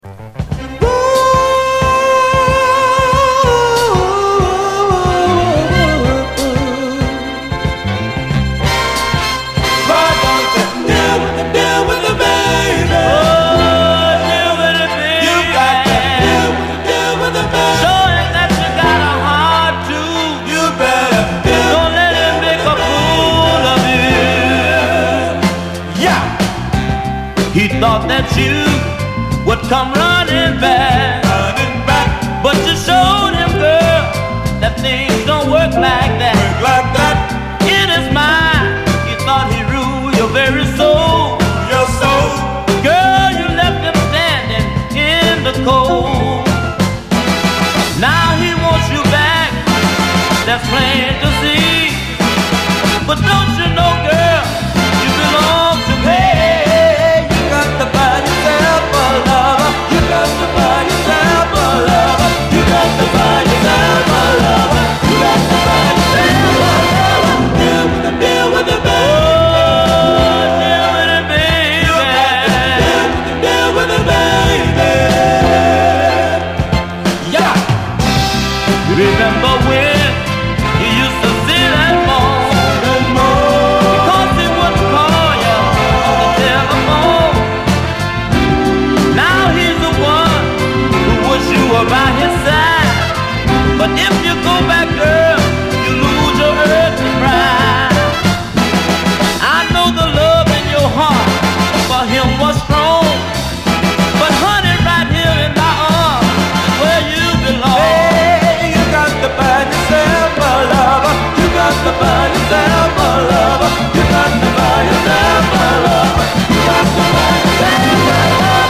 SOUL, 60's SOUL, 70's～ SOUL, 7INCH
黄金期の輝きを放つハーモニック・クロスオーヴァー・ソウル
多彩なコーラスやアレンジ・ワークに参りました！